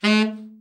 TENOR SN  14.wav